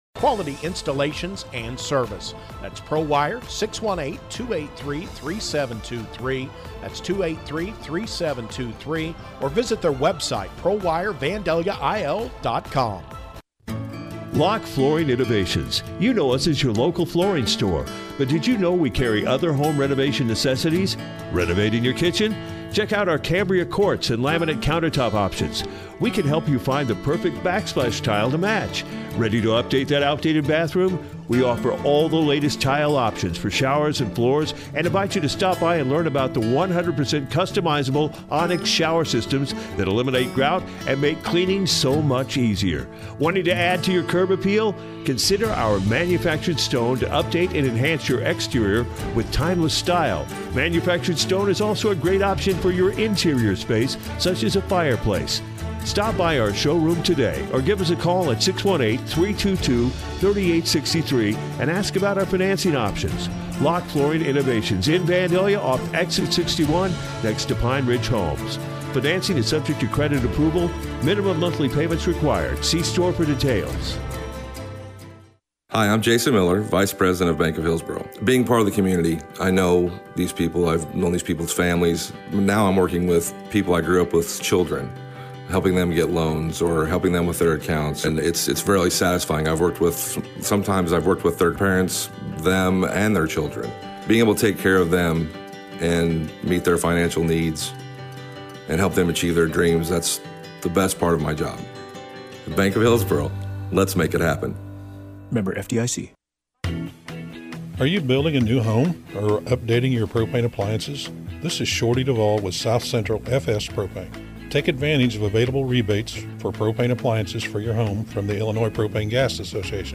Full Game Audio-CHBC vs. WSS at NTC Girls Basketball Tournament